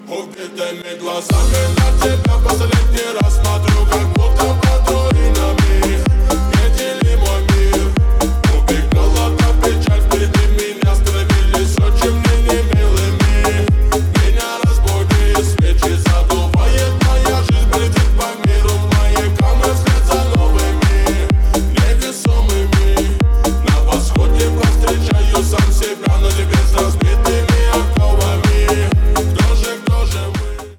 Ремикс
громкие # клубные